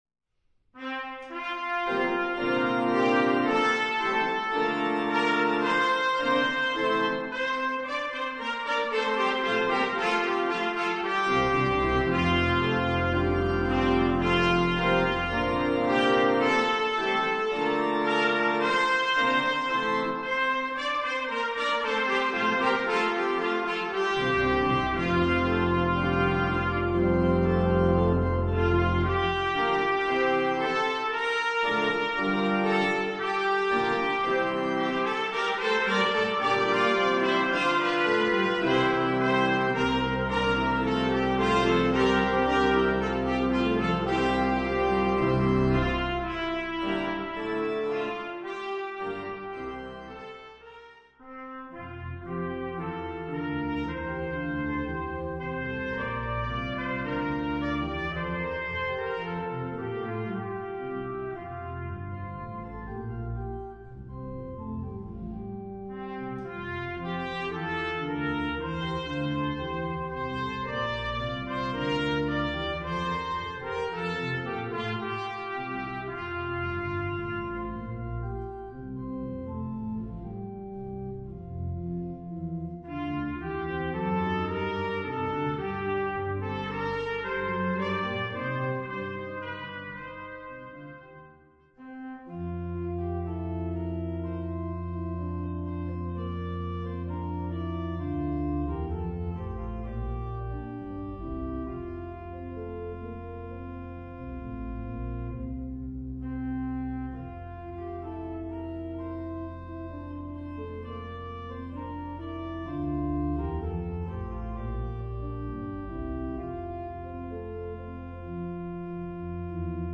Voicing: Trumpet Duet